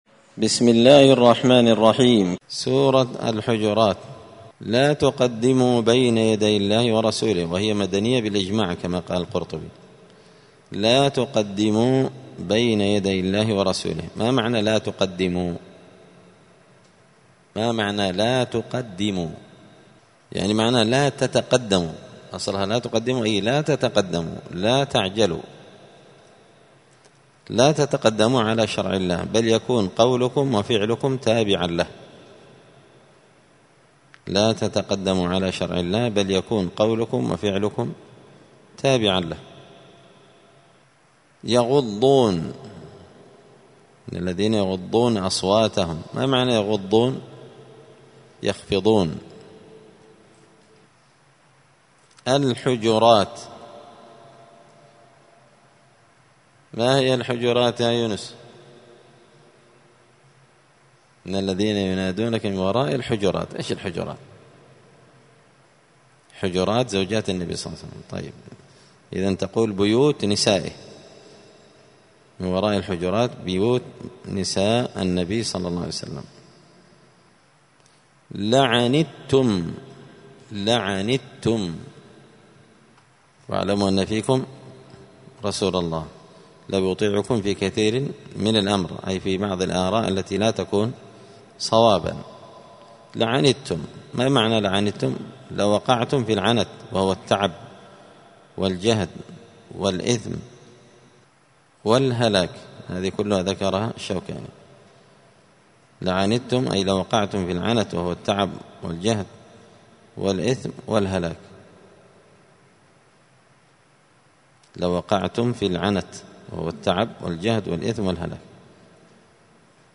دار الحديث السلفية بمسجد الفرقان بقشن المهرة اليمن